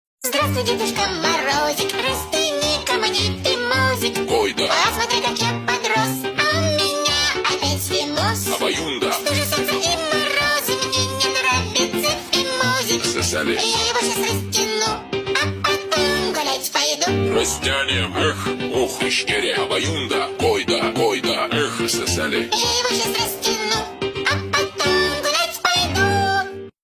Новая песня про новый год в современном слненге и стиле